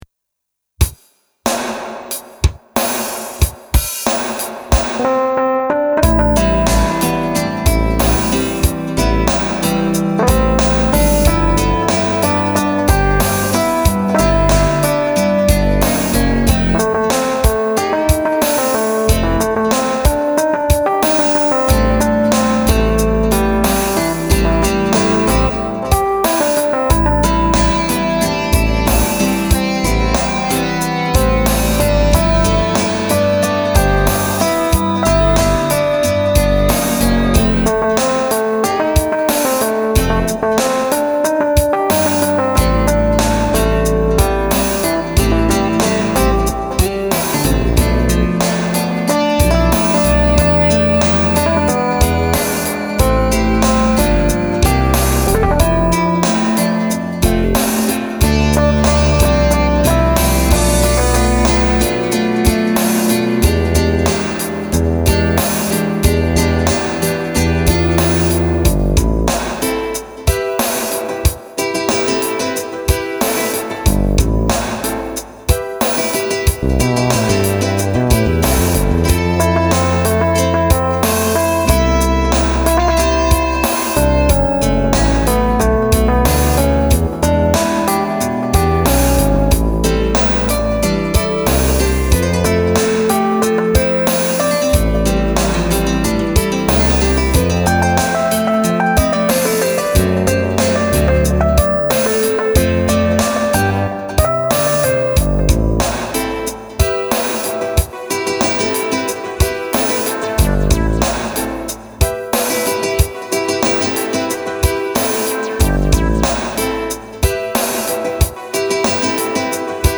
音源は、ＳＣ８８２０を購入したので、さらに良くなっているはず。
生ギターをピックでガンガン弾くパターンを書きたくて作った曲。